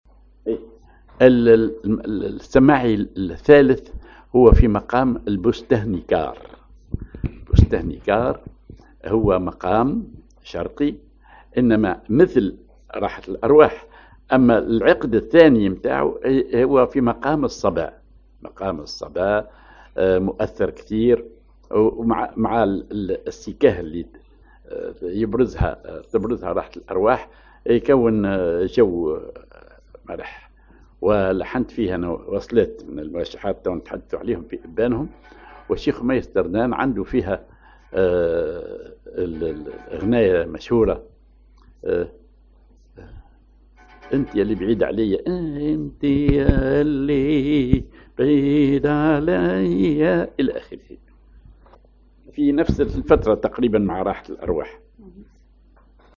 Maqam ar بسته نكار
Rhythm ID سماعي ثقيل
genre سماعي